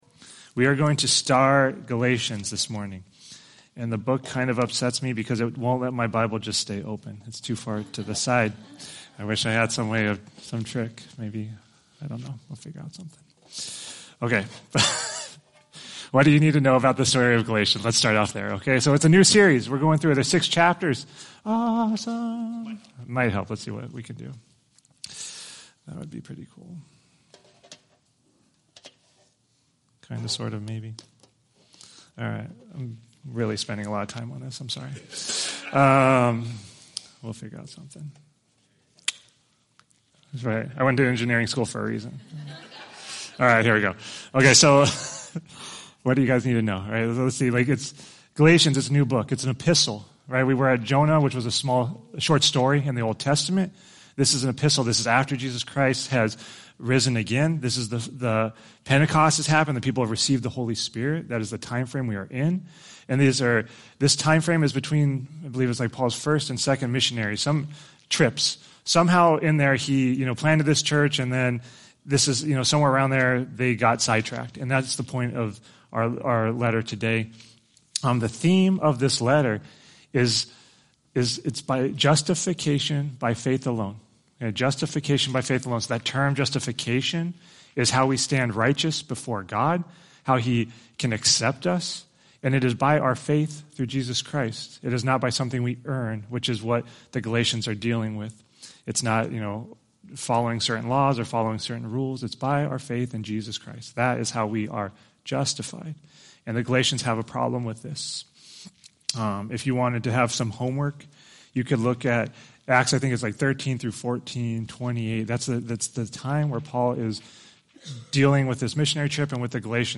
Today, we start a series of sermons on Galatians.
Sunday Worship